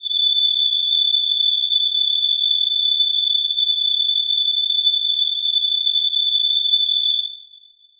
Choir Piano
A7.wav